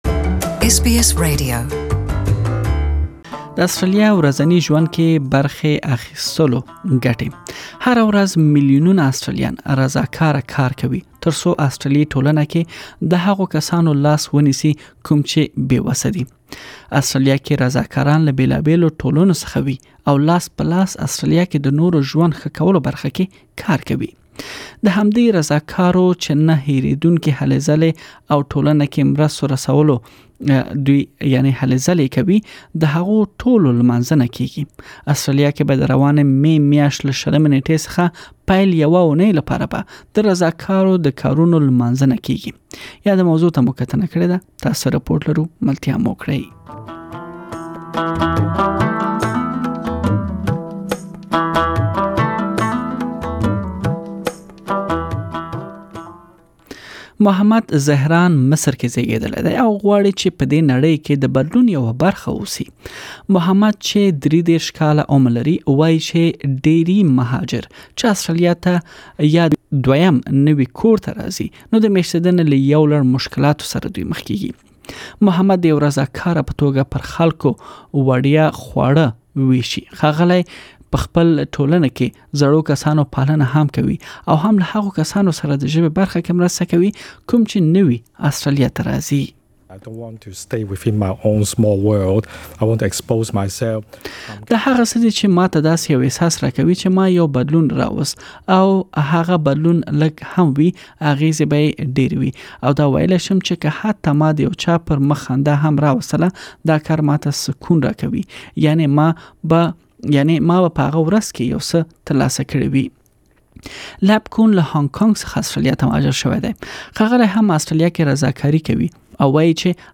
تاسو ته مو اسټراليا کې د رضا کارو په توګه کار کولو ګټې راخيستي بشپړ رپوټ واورئ.